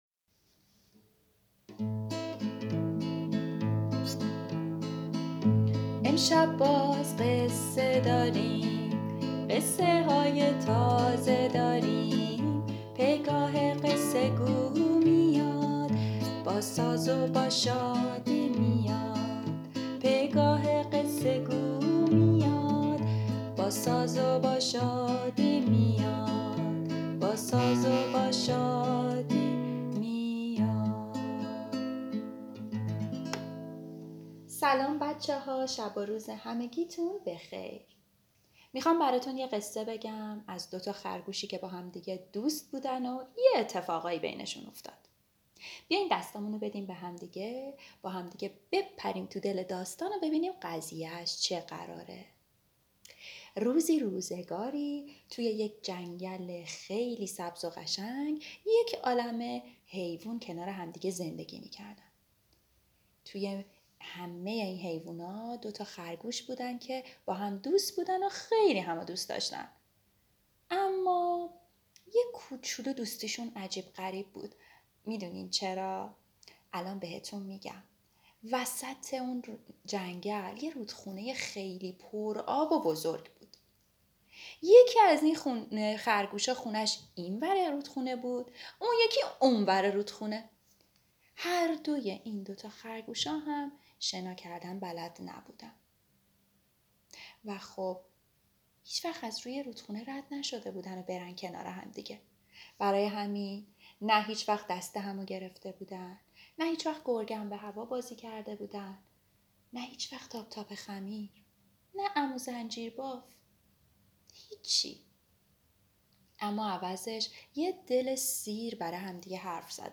قصه صوتی کودکان دیدگاه شما 2,993 بازدید